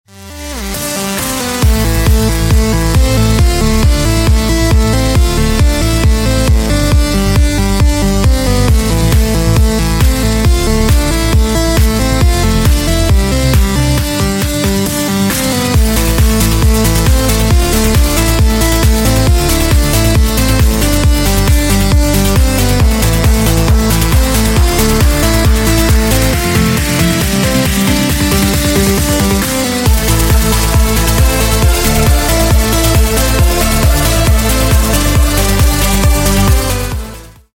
Клубные Рингтоны » # Рингтоны Без Слов
Рингтоны Электроника